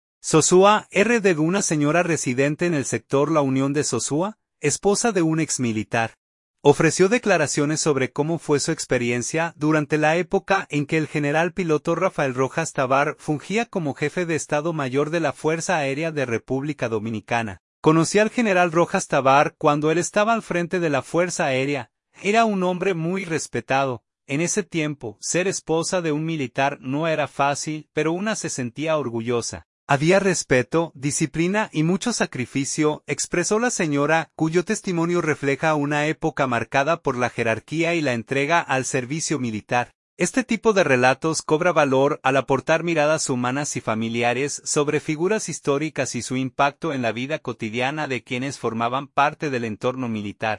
Sosúa, R.D.– Una señora residente en el sector La Unión de Sosúa, esposa de un exmilitar, ofreció declaraciones sobre cómo fue su experiencia durante la época en que el general Piloto Rafael Rojas Tabar fungía como jefe de Estado Mayor de la Fuerza Aérea de República Dominicana.